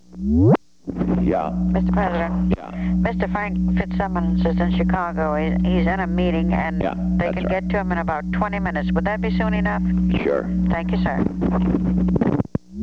Secret White House Tapes
Location: White House Telephone
The White House operator talked with the President.